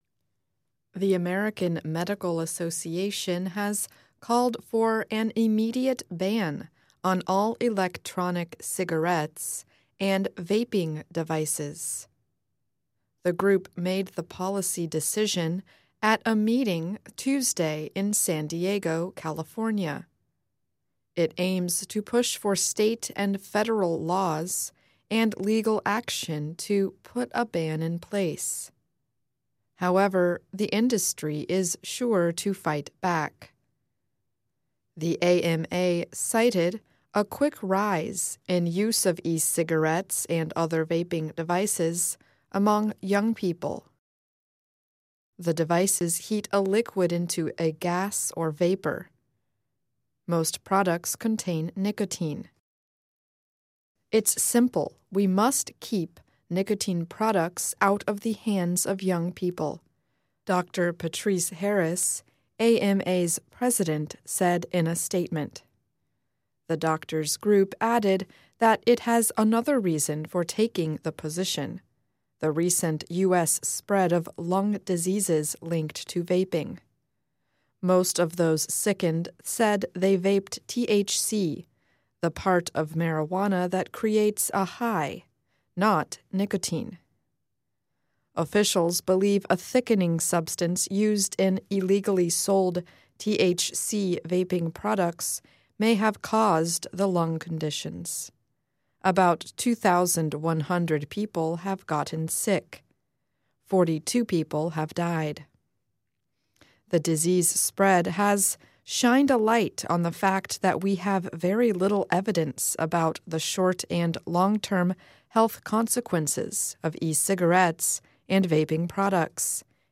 News
慢速英语:美国医学协会呼吁全面禁止电子烟产品